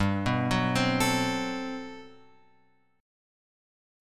GM11 chord {3 2 4 5 x 5} chord